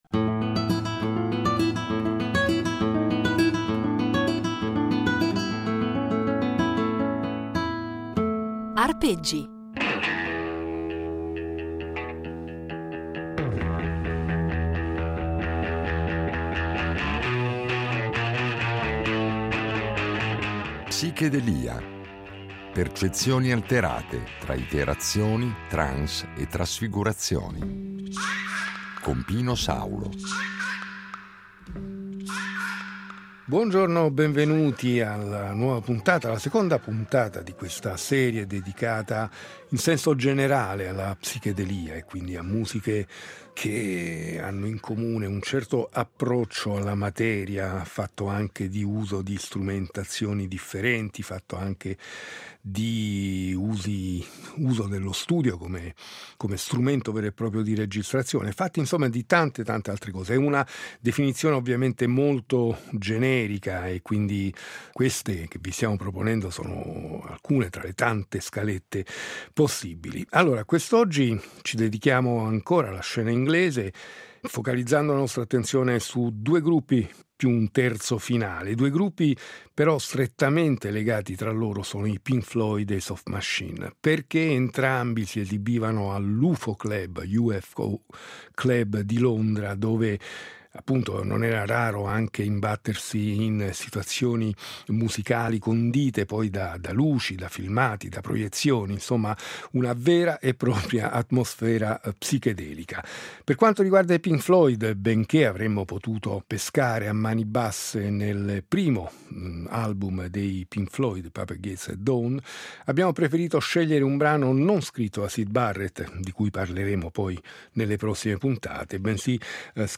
L’oggetto di questa serie di puntate è un lungo percorso che ci porterà ad esplorare e ad ascoltare musiche che in qualche misura possiamo definire come psichedeliche. Termine volutamente ambiguo, che va a indicare un insieme di musiche nate intorno alla metà degli anni ‘60 aventi in comune un riferimento alle droghe allucinogene, ma nelle quali la musica incorpora elementi provenienti da altre culture musicali - dall’oriente soprattutto, con l’utilizzo di strumenti come il sitar o le tabla - effetti sonori, tecniche di registrazione che pongono in qualche modo elevano lo studio di registrazione al rango di uno strumento al pari degli altri, con l’uso massiccio di loop, nastri al contrario, riverbero, e ancora elementi del jazz modale e più sperimentale, droni, il tutto condito da luci stroboscopiche e testi surreali o con riferimenti più o meno velati a esperienze allucinatorie.